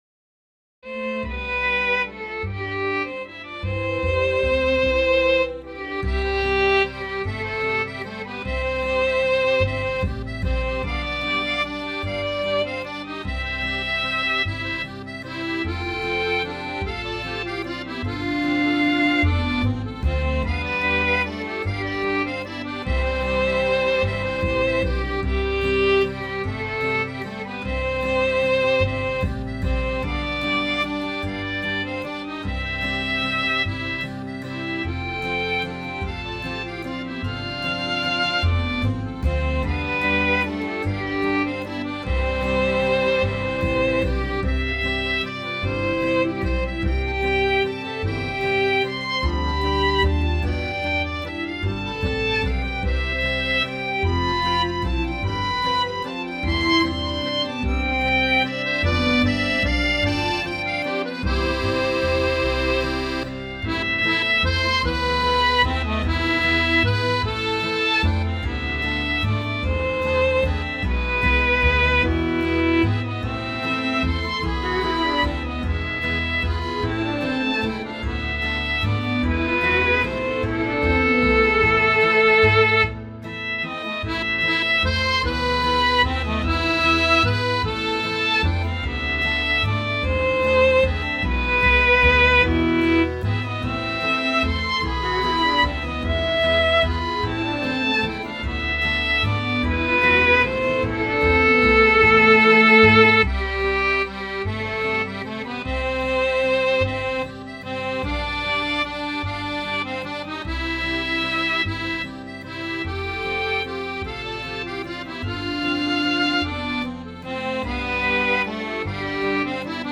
Nr 93  Vakre Nord-Odal i ..   - Vals.